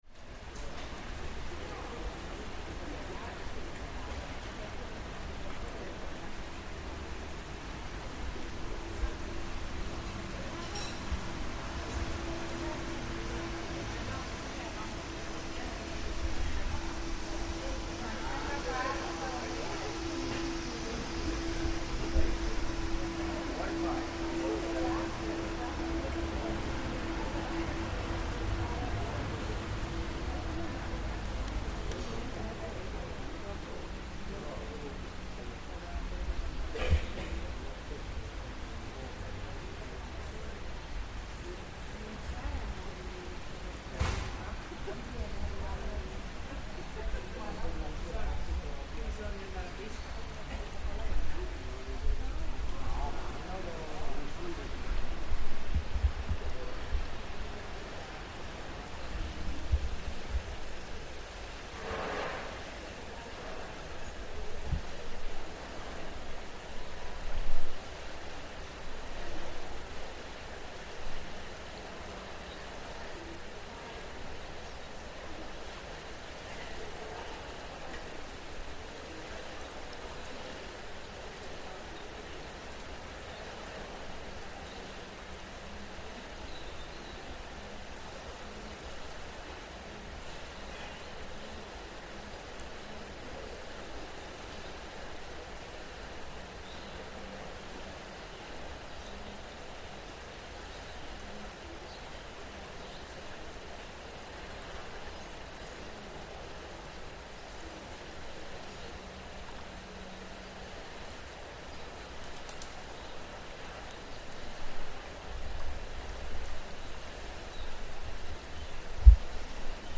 A very hot August afternoon in Treviso, Italy. The city is on its post-lunch break, while the sun is at its hottest - shops are closed, there are very few cars or pedestrians, and everyone is seeking shade.
We sit under a tree next to the river and record the sounds of a city a rest in the summer.